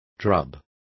Complete with pronunciation of the translation of drubs.